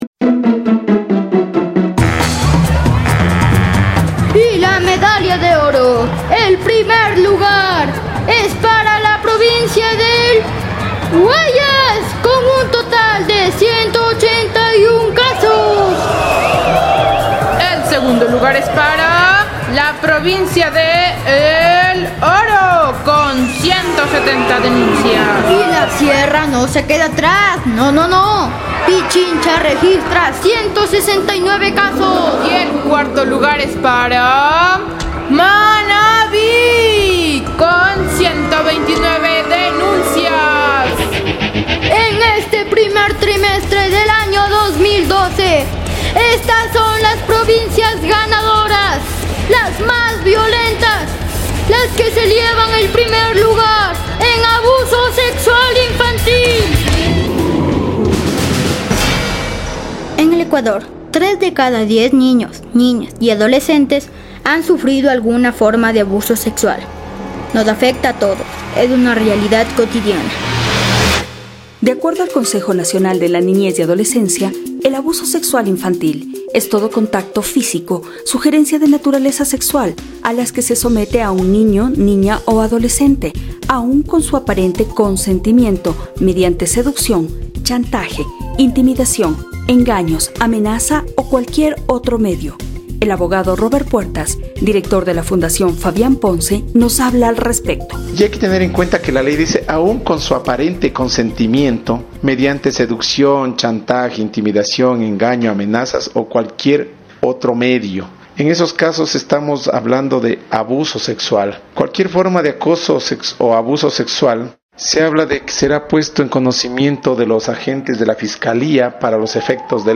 Uno de los reportajes ganadores fue “Si me crees, me salvas”